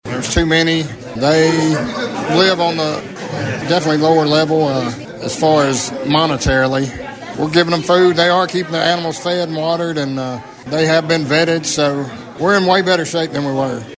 Sheriff McDade says the dogs have been taken care of so far…